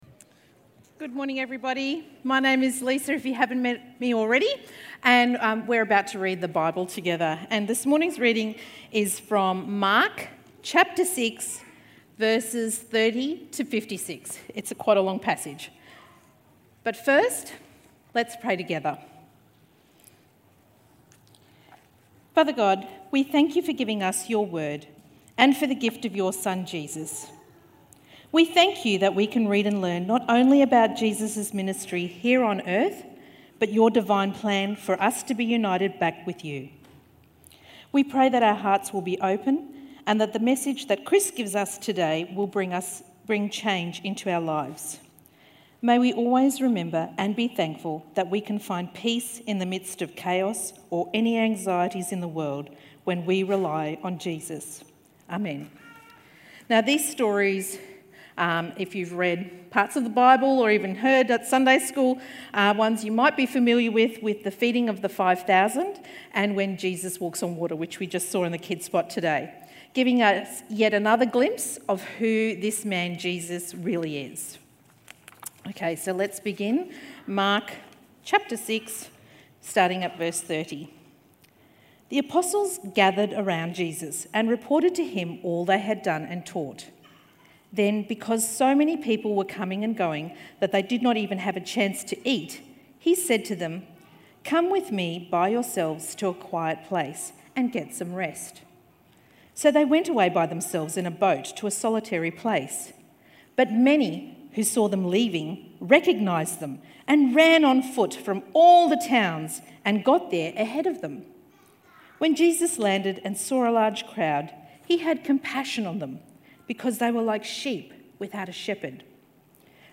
KingOverCreation-Talk.mp3